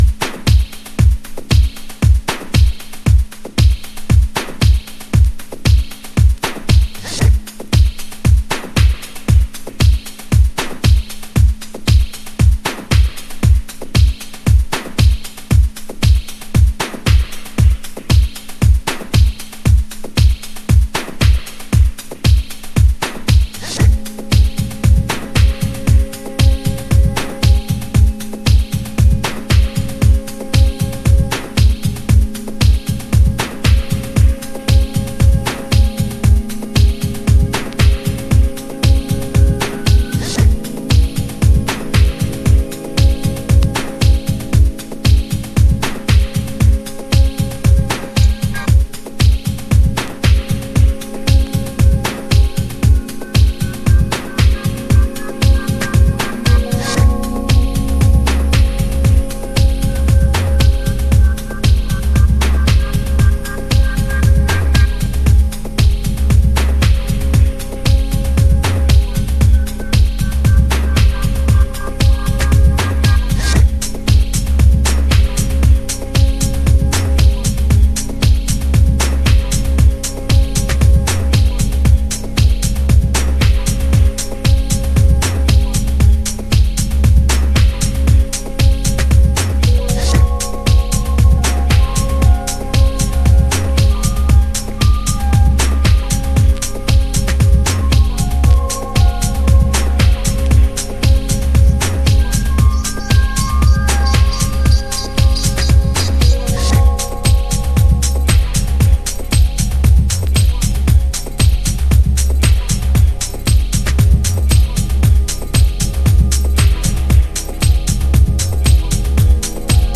House / Techno
スペースシンセとブギーグルーヴのA1、スペースダビーハウスB1、共に2000年初頭のリリース。